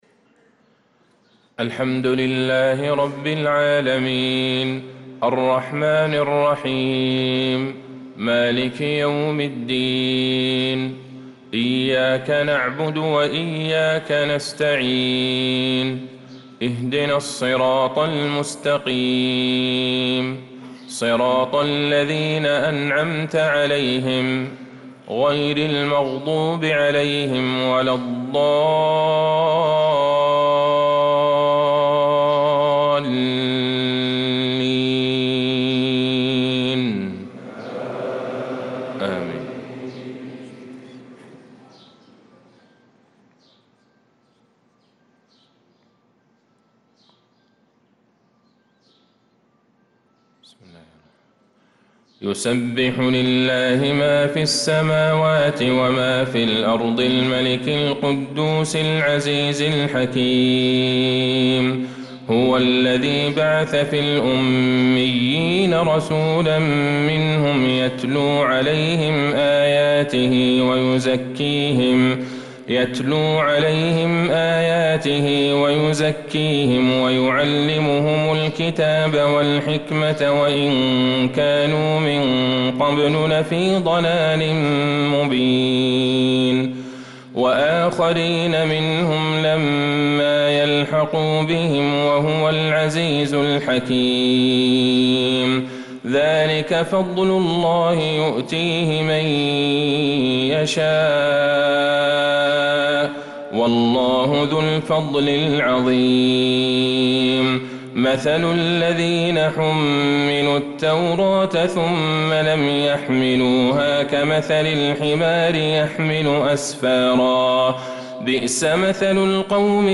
صلاة الفجر للقارئ عبدالله البعيجان 23 شوال 1445 هـ
تِلَاوَات الْحَرَمَيْن .